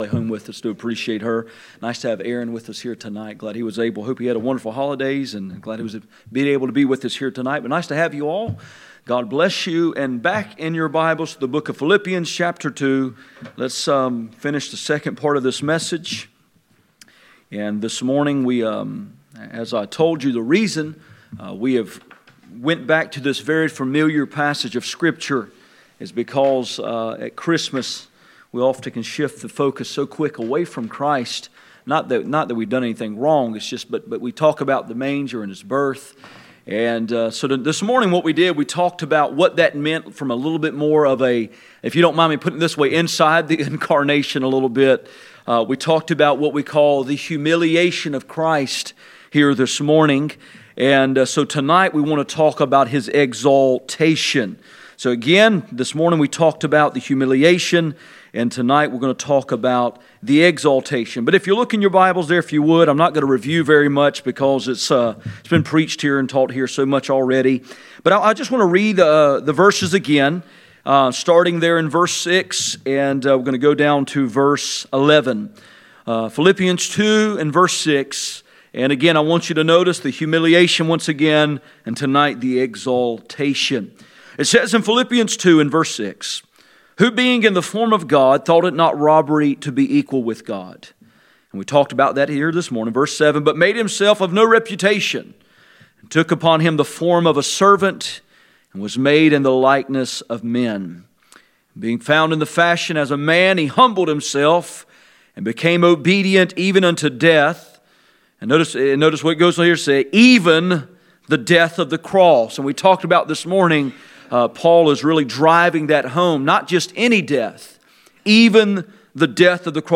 Passage: Philippians 2:5-11 Service Type: Sunday Evening